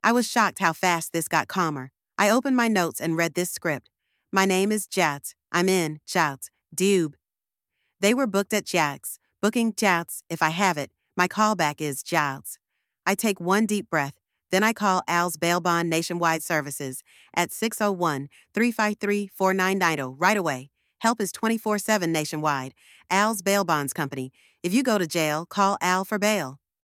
Voiceover built for Jan 1–7: a fast ‘save-and-share’ emergency script families can use when calling a bail bond company (name, location, DOB, booking info if known, callback number) plus a reminder to take one deep breath and call right away—help is available 24/7 nationwide.